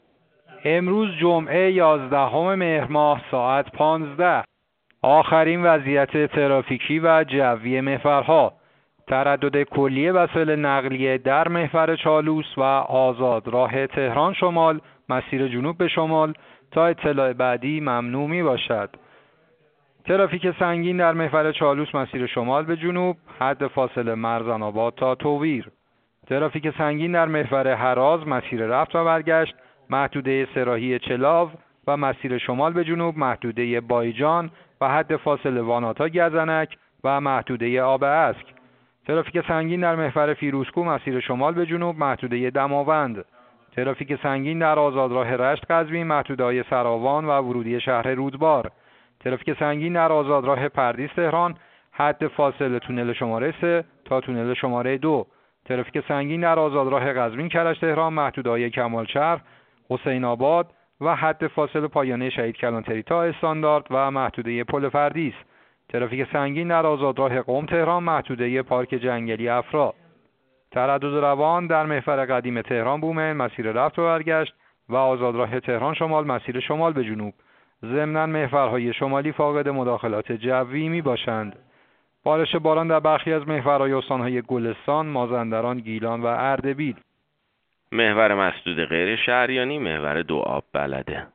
گزارش رادیو اینترنتی از آخرین وضعیت ترافیکی جاده‌ها ساعت ۱۵ یازدهم مهر؛